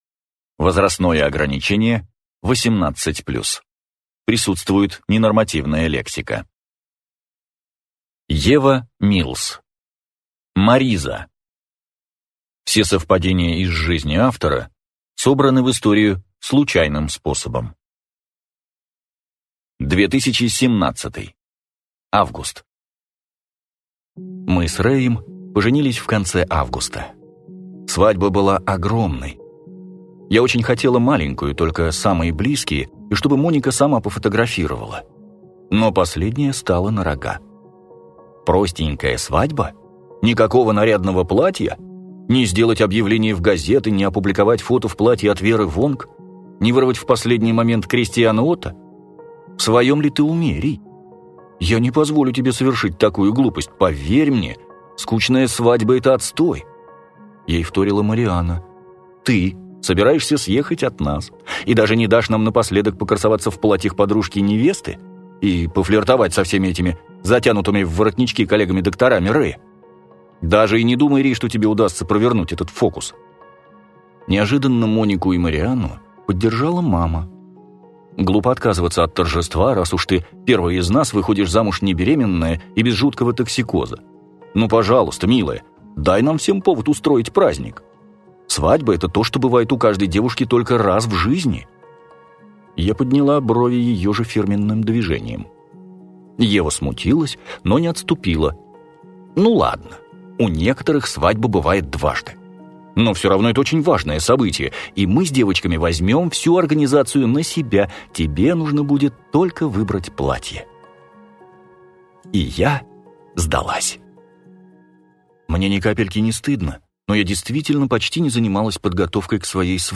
Аудиокнига Мариза | Библиотека аудиокниг